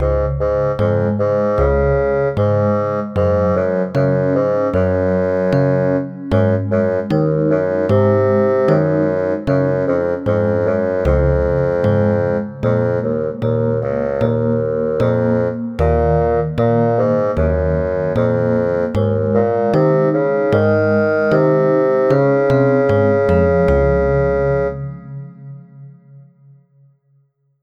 Children's Theme, Theater Music